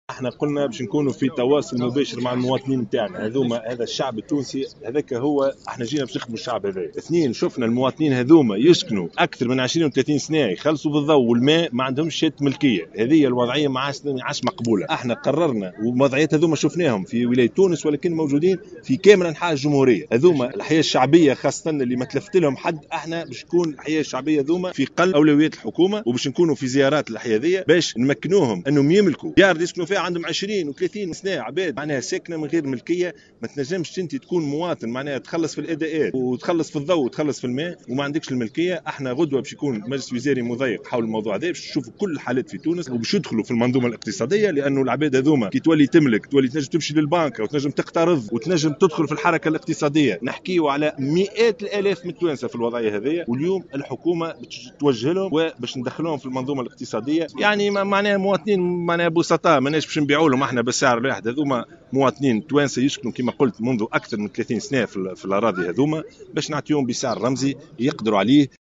وأكد الشاهد، في تصريح لمراسلة الجوهرة أف أم، على هامش زيارة ميدانية، أداها صباح اليوم الأحد إلى أحياء "بلعور" بفوشانة و"زيو زيو" ببرج الطويل و"حي النحلي" باريانة، عن تمليك مواطنين لأراضي الدولة التي أقاموا عليها منازلهم، وهو ما سيتم الإعلان عنه رسميا خلال مجلس وزاري في الغرض سيعقد غدا الاثنين.